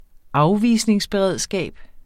Udtale [ ˈɑwˌviˀsneŋs- ]